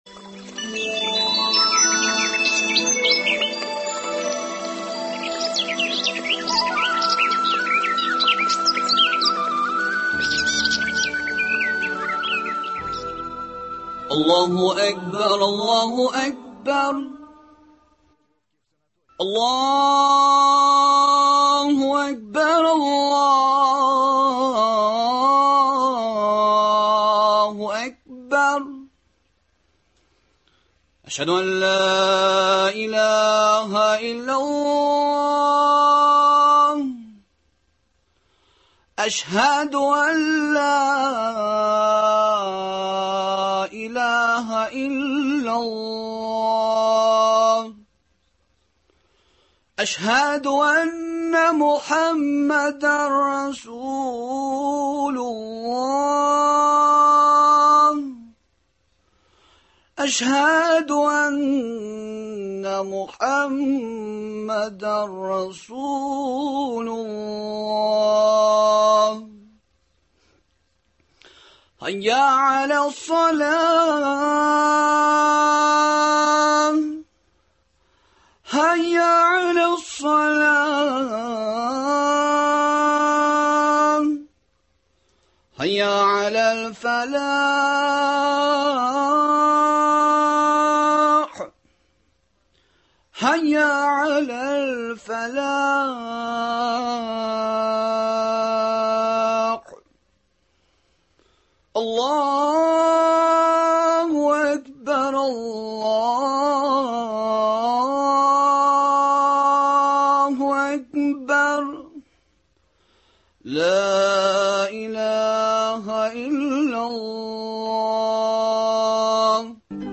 Әңгәмәдән нәфел уразалары, нәфел намазлары, алар өчен бирелә торган әҗер-саваплар, бәхет-сәгадәт ишеге — шушы һәм башка айларда кылына торган гыйбадәтләрнең сере турында белә аласыз.